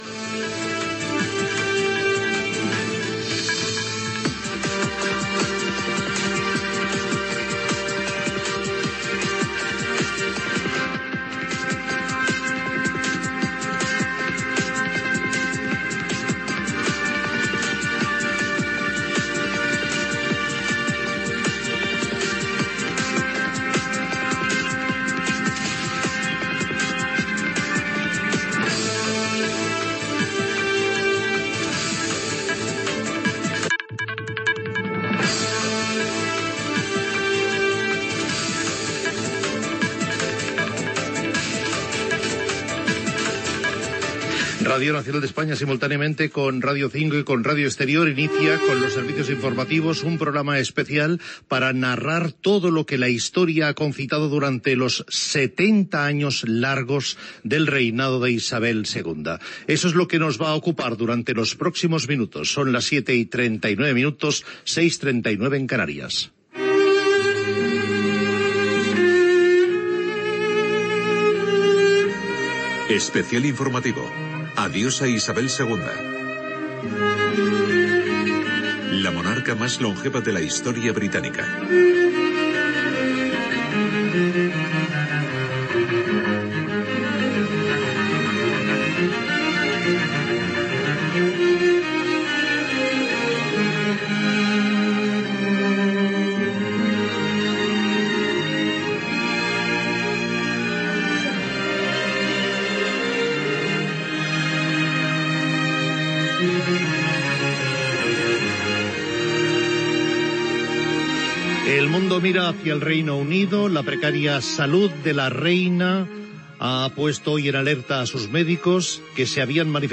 Especial informativo. Adiós a Isabel II Descripció Especial informatiu sobre la mort de la reina del Regne Unit Isabel II. Presentació, hora, careta, informació de la situació, connexió amb Londres
Gènere radiofònic Informatiu